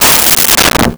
Metal Strike 05
Metal Strike 05.wav